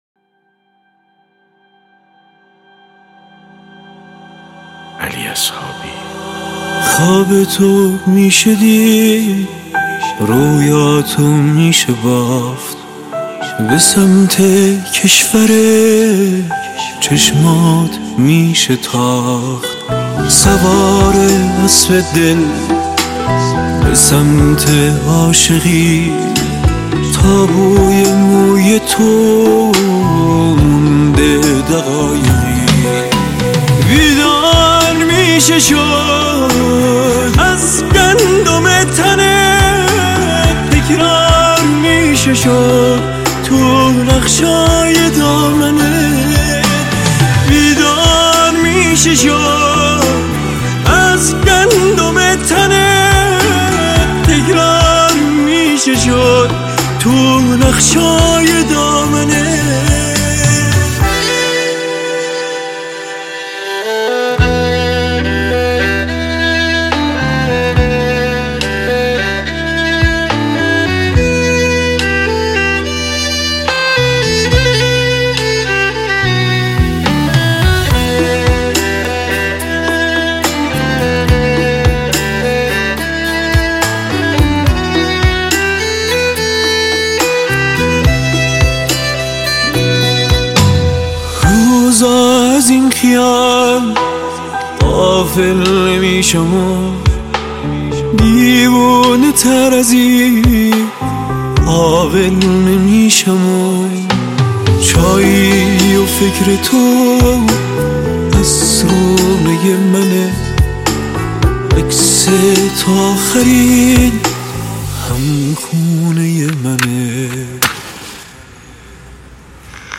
آهنگهای پاپ فارسی
کیفیت بالا